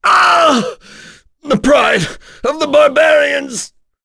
Gau-Vox_Dead.wav